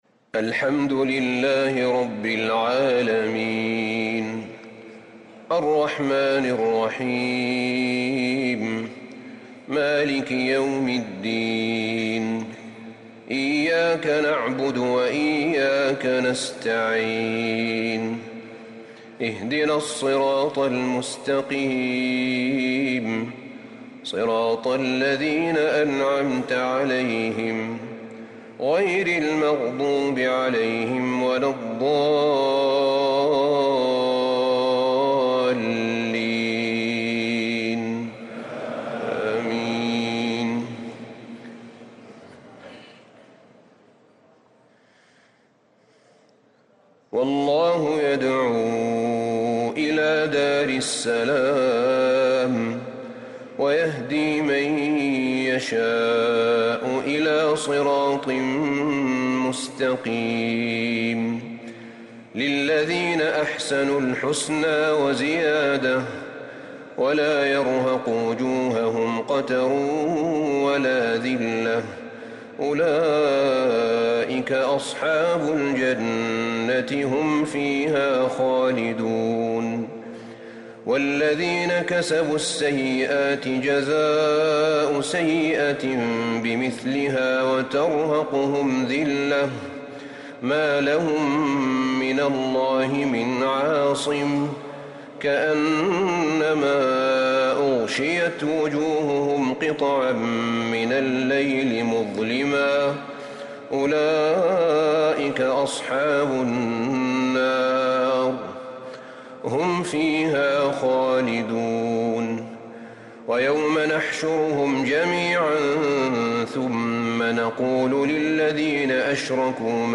تراويح ليلة 15 رمضان 1444هـ من سورة يونس {25-109} | taraweeh 15st Ramadan niqht 1444H Surah Yunus > تراويح الحرم النبوي عام 1444 🕌 > التراويح - تلاوات الحرمين